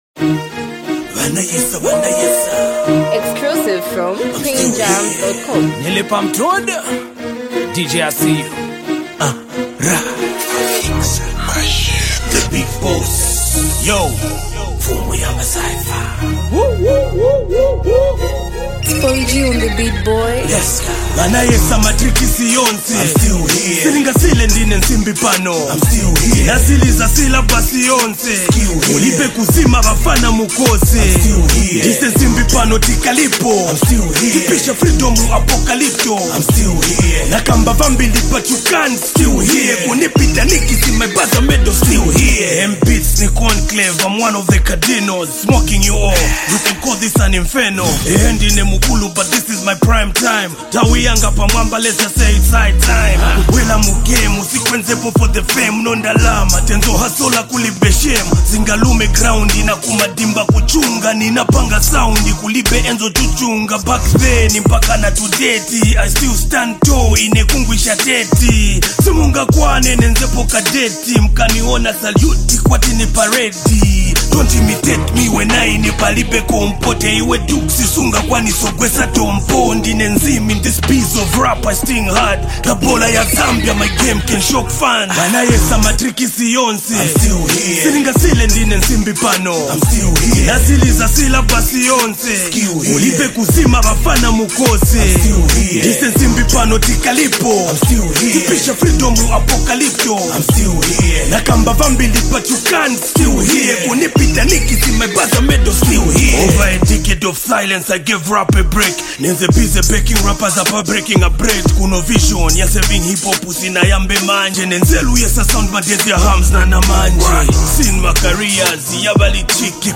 powerful and reflective hip-hop track